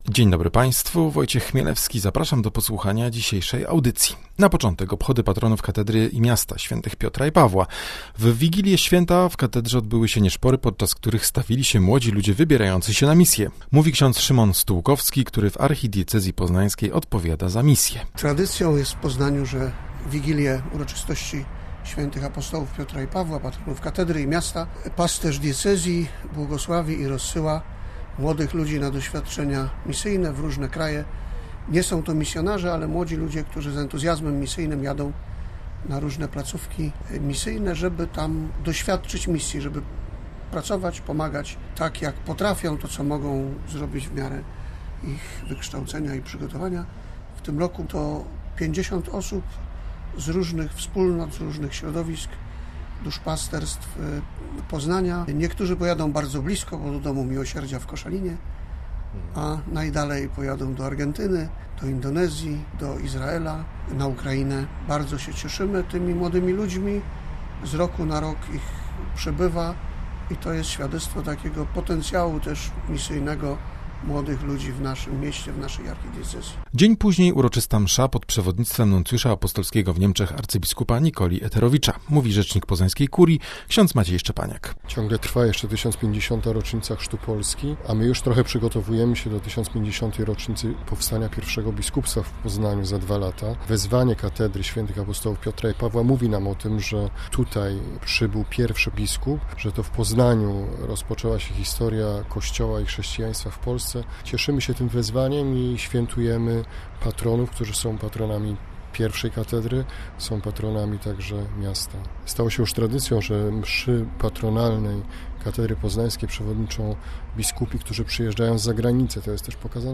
Rozmowa o obchodach Święta Patronów Miasta Poznania i o książce o Myszorku i papieżu Franciszku.